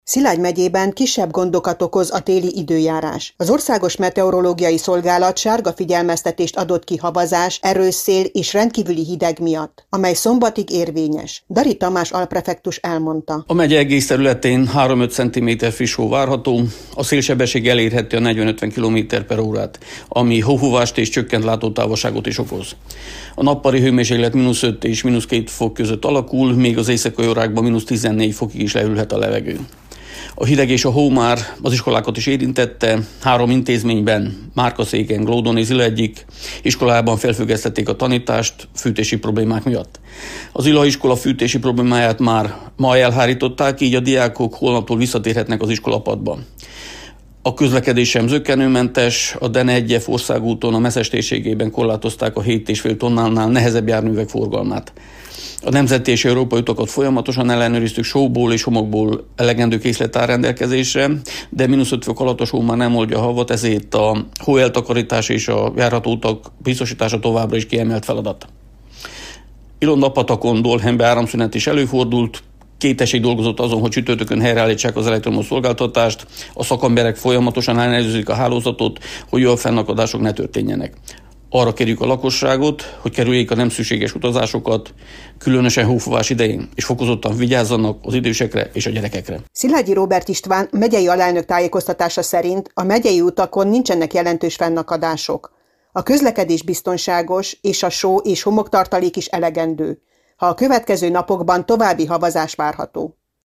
Szilágy megyében kisebb fennakadásokkal jár a téli időjárás: havazás, erős szél és fagy várható szombatig. Az utak járhatóak, a só- és homoktartalék elegendő – tájékoztatott Dari Tamás alprefektus.